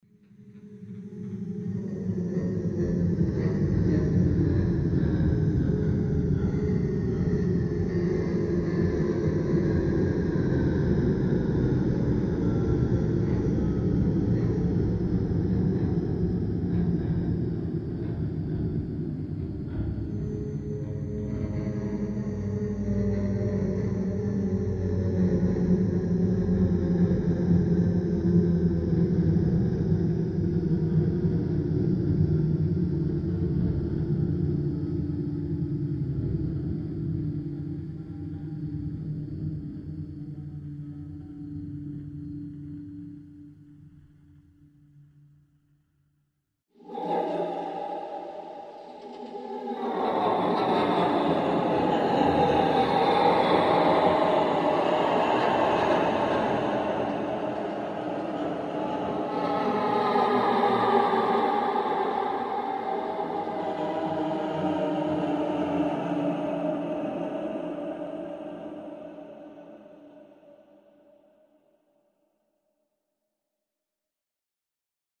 Гул зловещей атмосферы